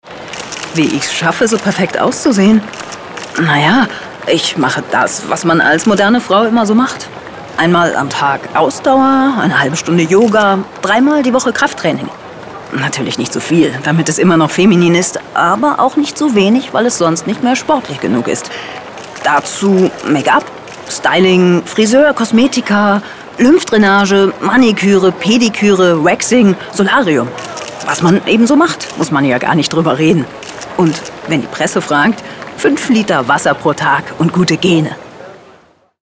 markant, sehr variabel, dunkel, sonor, souverän
Mittel plus (35-65)
Norddeutsch
Werbung - Beauty-Geheimnisse
Comment (Kommentar), Commercial (Werbung), Off